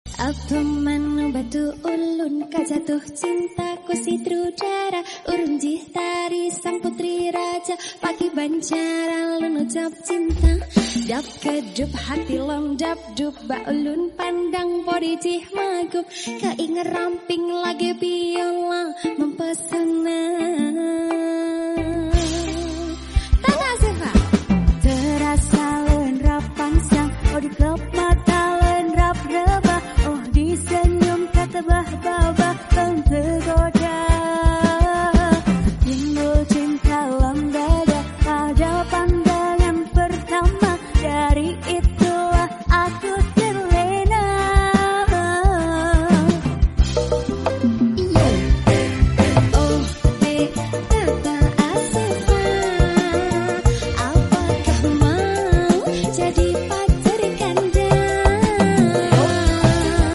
soundnya Vario biru 😍 . sound effects free download